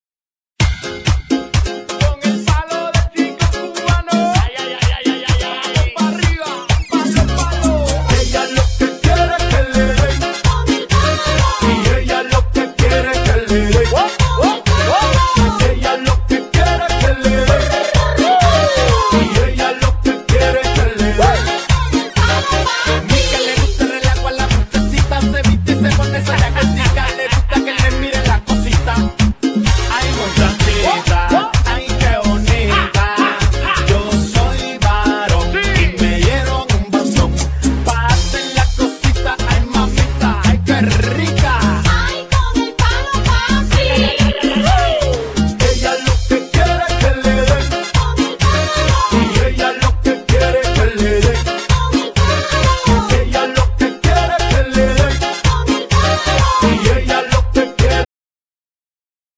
Genere : Pop latino